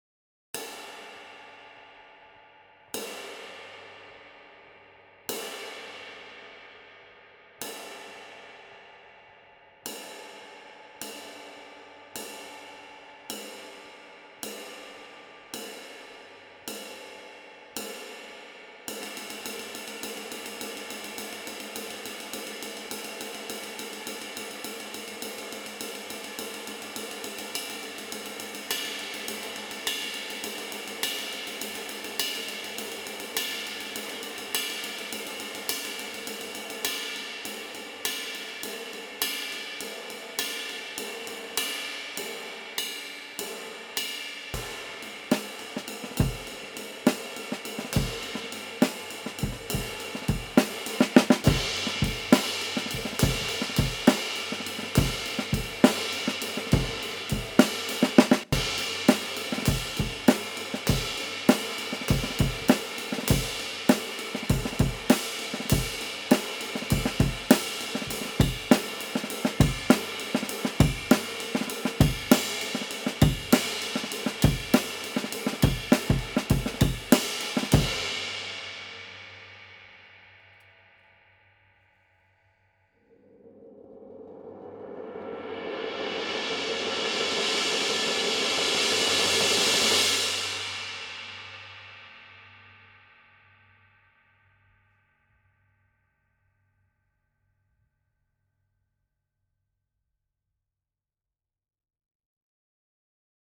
Hand-hammered from B20 bronze.